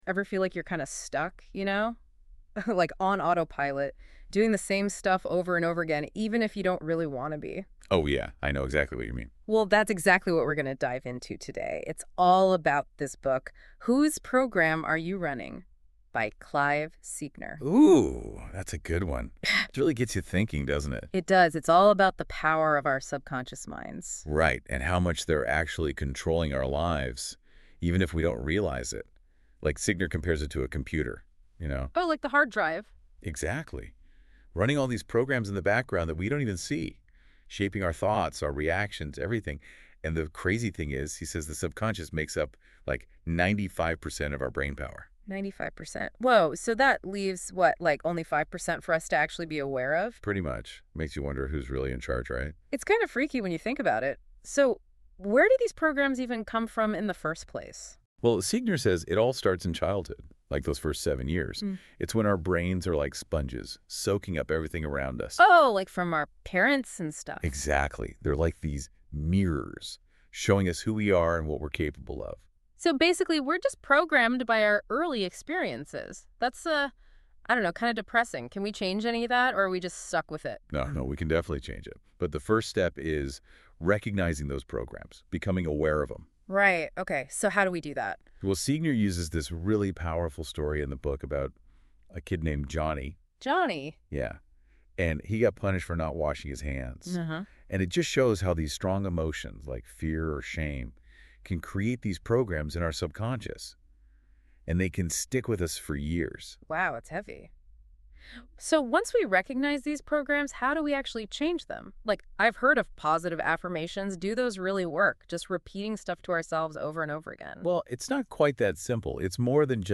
Listen to the AI generated audio